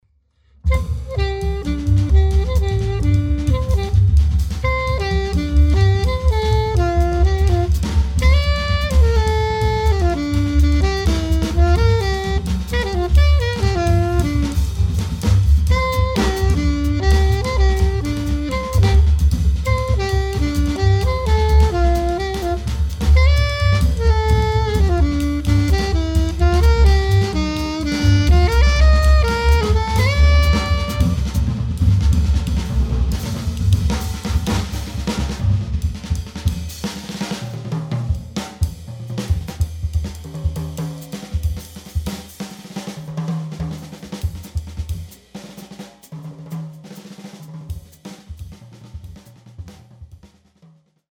sax
bass
drums